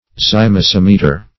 Search Result for " zymosimeter" : The Collaborative International Dictionary of English v.0.48: Zymometer \Zy*mom"e*ter\, Zymosimeter \Zy`mo*sim"e*ter\, n. [Gr.
zymosimeter.mp3